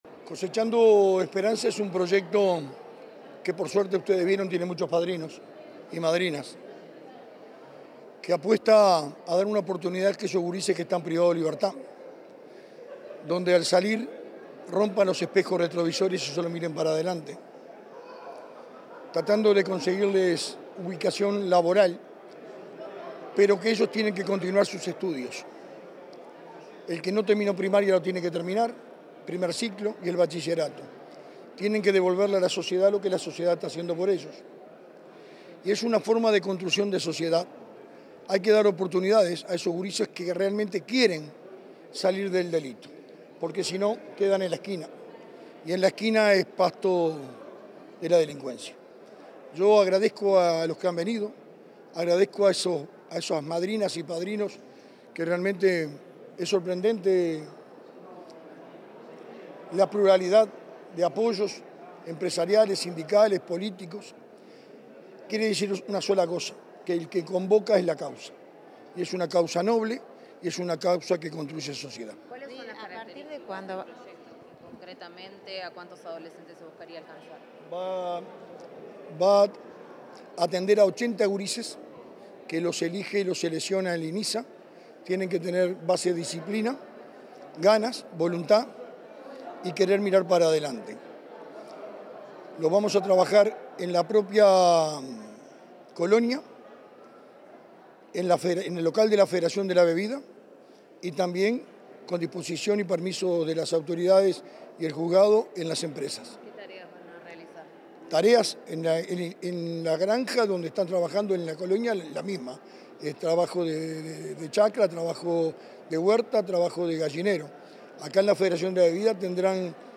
Declaraciones del impulsor del programa Cosechando Esperanzas, Richard Read 24/11/2025 Compartir Facebook X Copiar enlace WhatsApp LinkedIn En declaraciones a la prensa, Richard Read, impulsor del programa Cosechando Esperanzas, destacó el compromiso interinstitucional que sostiene la iniciativa y afirmó que el objetivo es ofrecer herramientas concretas de trabajo y capacitación que permitan evitar la reincidencia de los participantes.